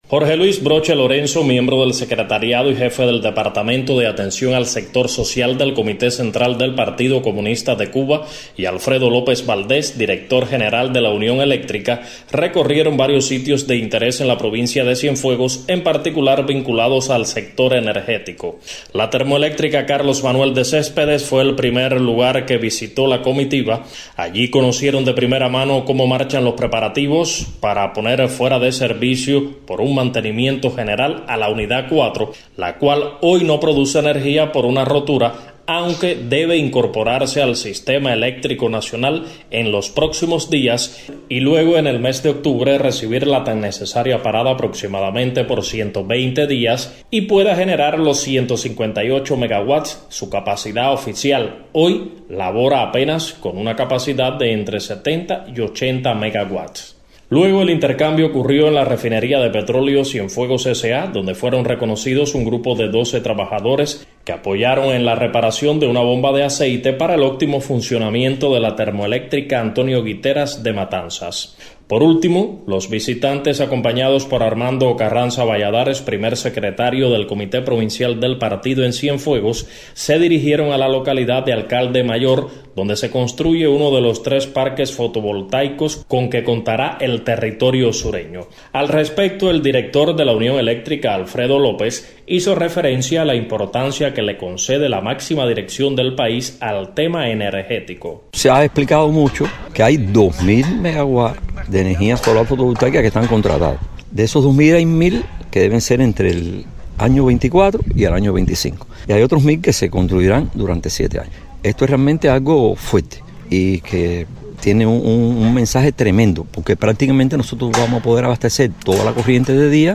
🎧 Declaraciones del director de la Unión Eléctrica en recorrido por Cienfuegos